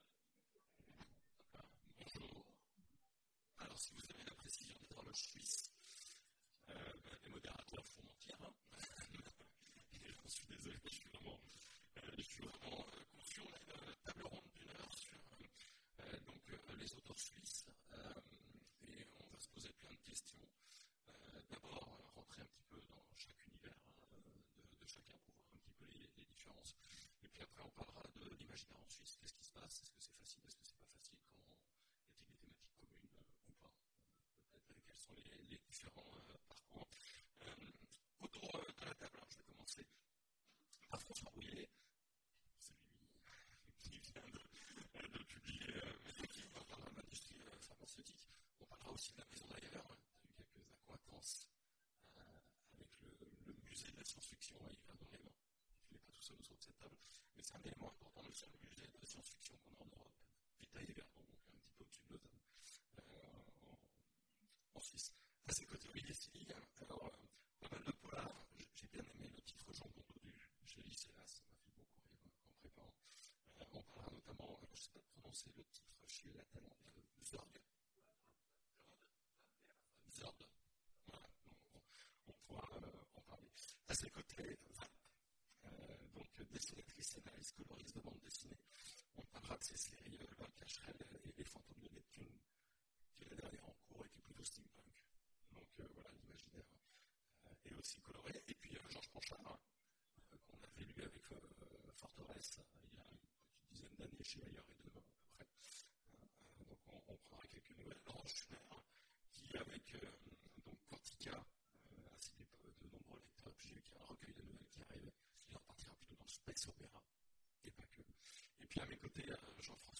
Imaginales 2017 : Conférence Les auteurs suisses… ils nous en mettent plein la vue !